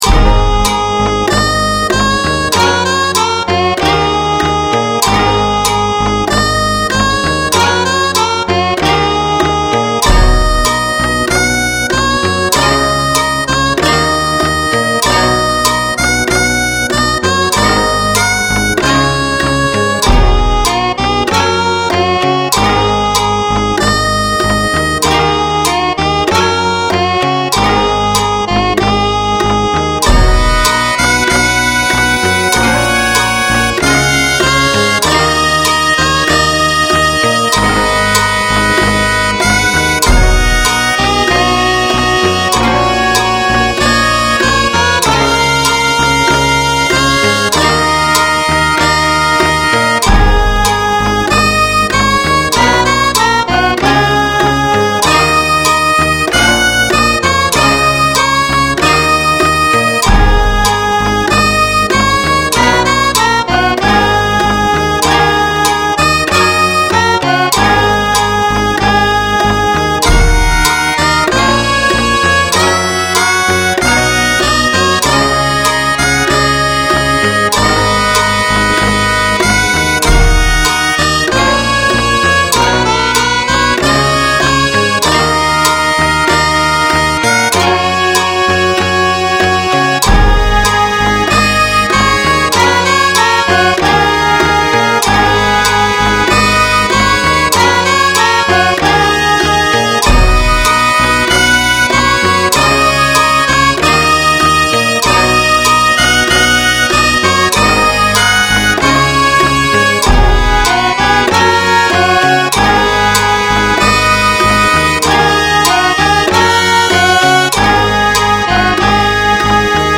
*レン シリーズ（和風な編成）